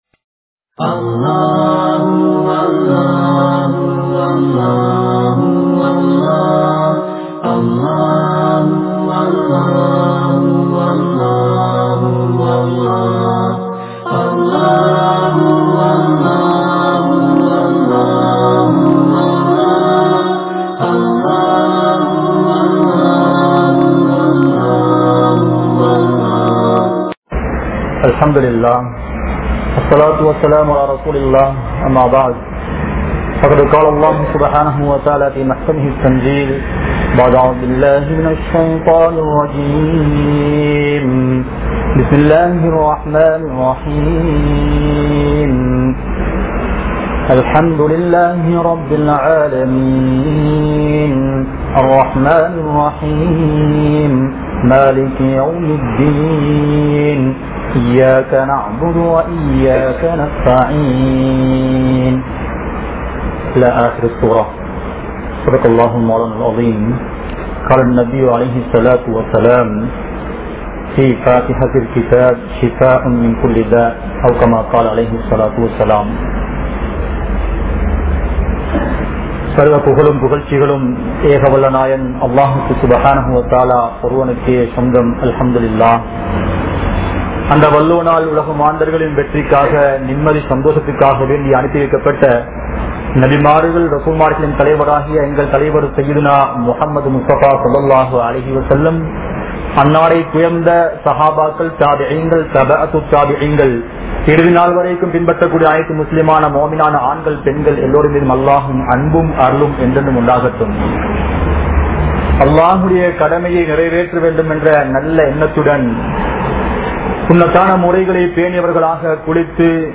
Surah Fathiha | Audio Bayans | All Ceylon Muslim Youth Community | Addalaichenai
Saliheen Jumua Masjidh